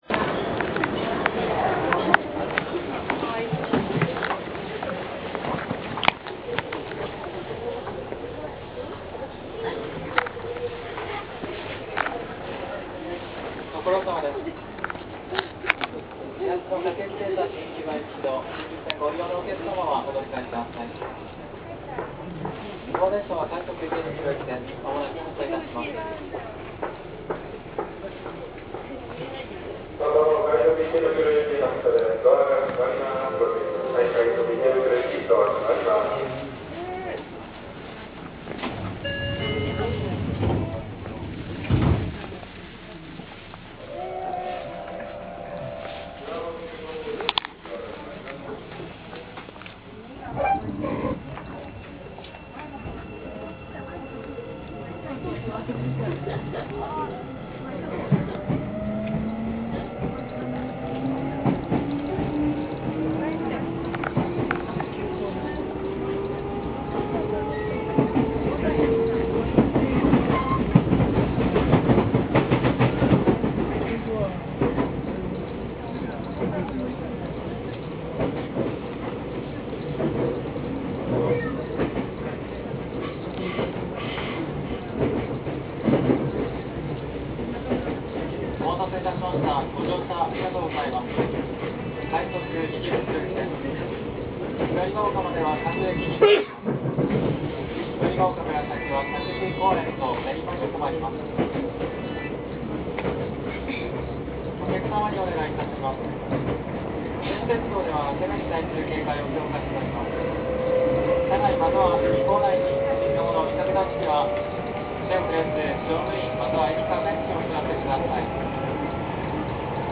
音声4　9000系の走行音